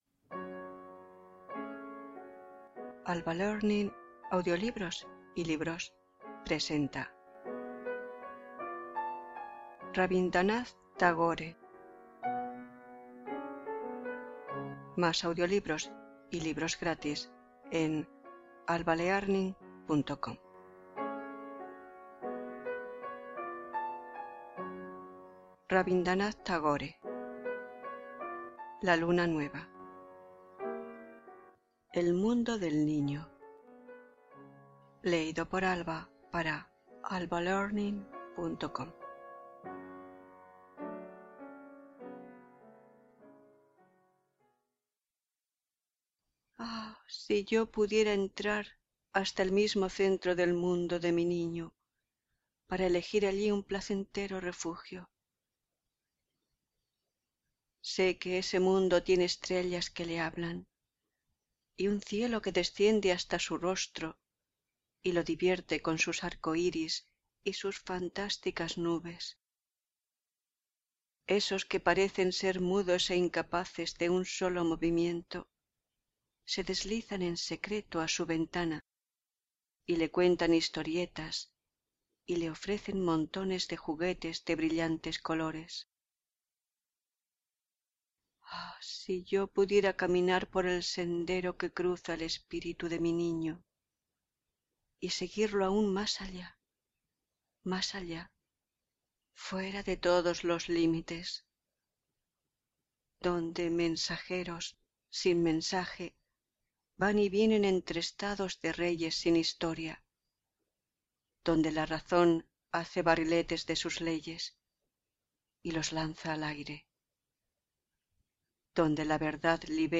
Música: Rebikov - Autumn Leaves op 29 No 3